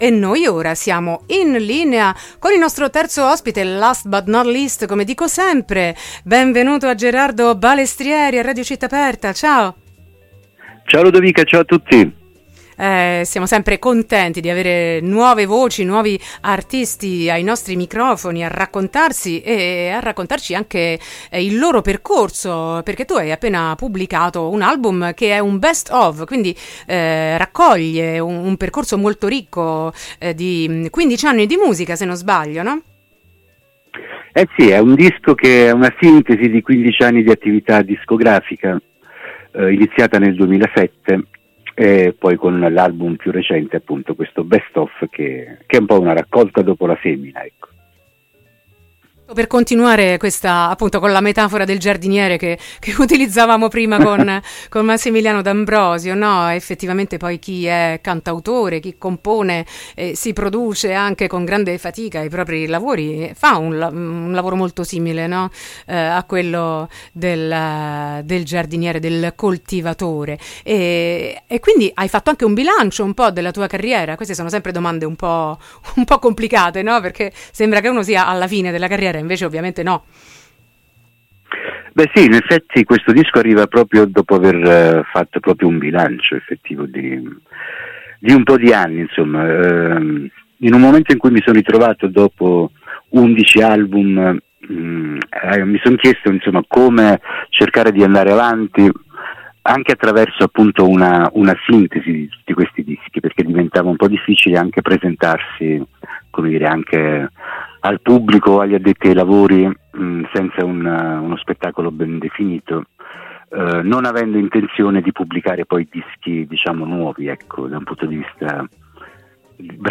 Quindici anni di musica: intervista